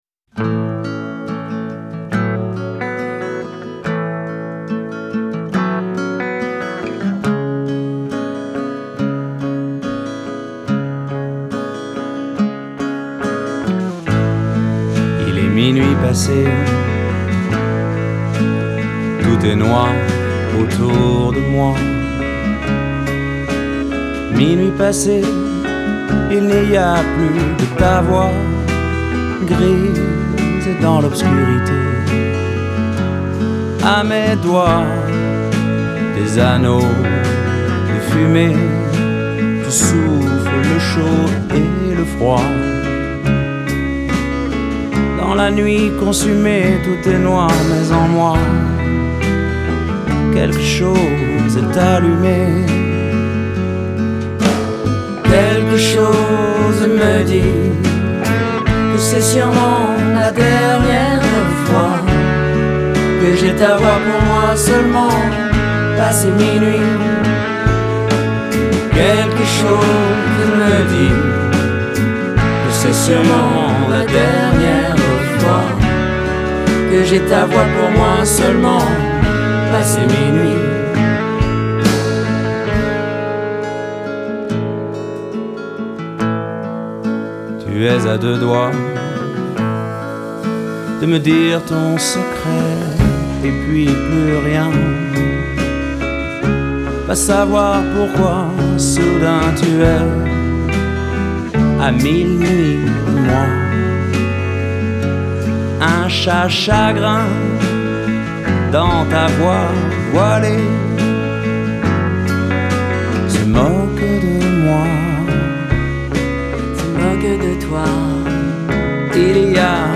enregistré en 2006 au studio du moulin
guitare, chant
basse
batterie